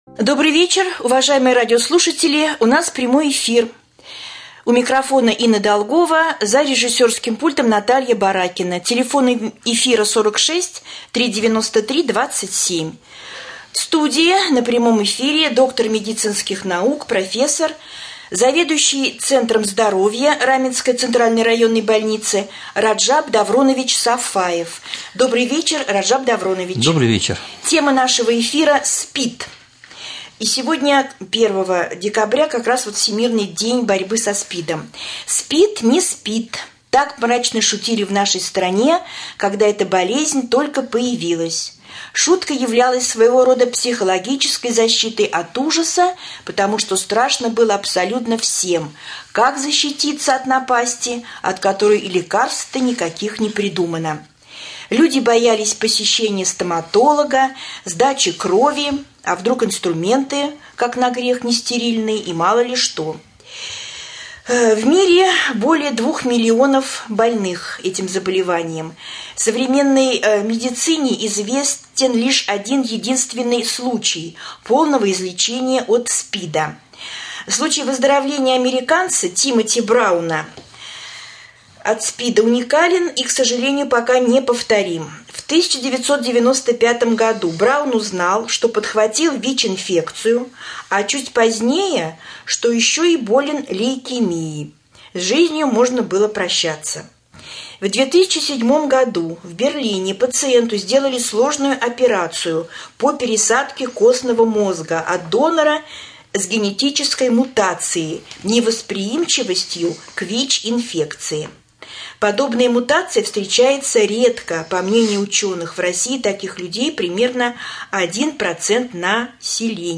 Прямой эфир с доктором медицинских наук